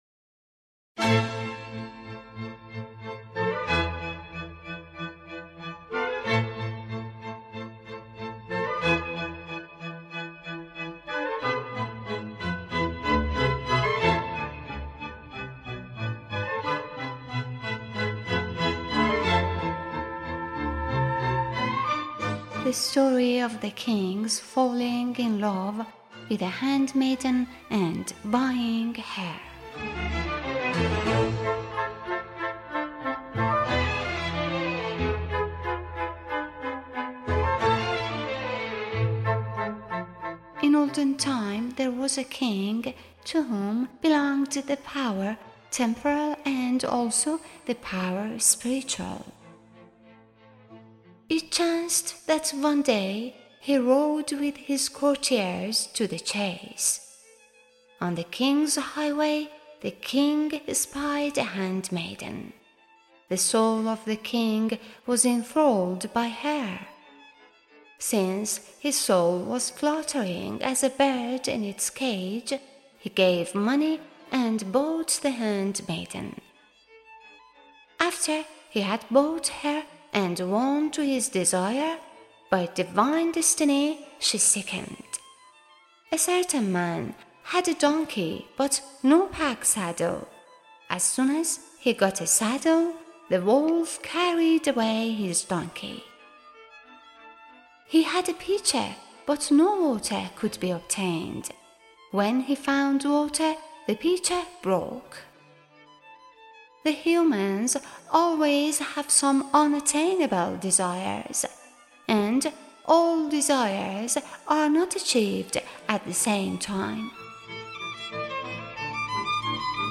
Music by: Abolhasan Saba, Hosein Dehlavi, Arsalan Kamkar